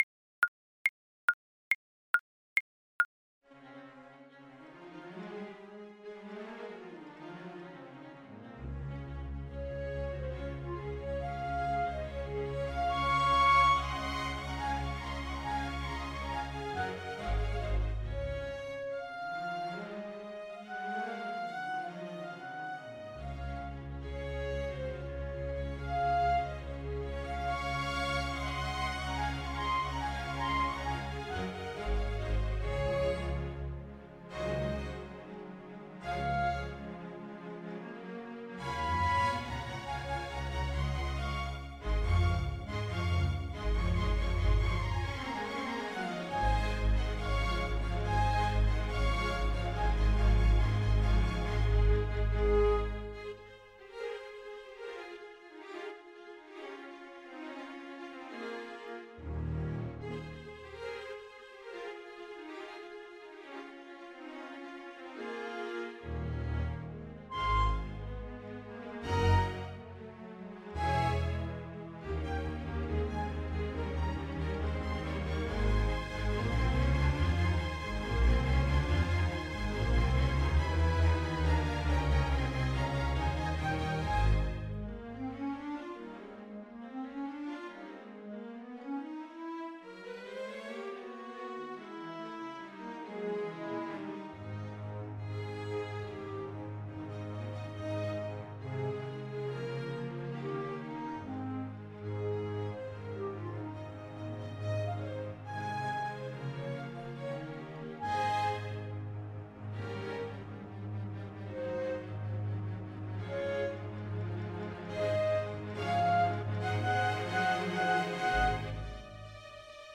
Alto Saxophone version
2/2 (View more 2/2 Music)
= 240 Presto (View more music marked Presto)
Classical (View more Classical Saxophone Music)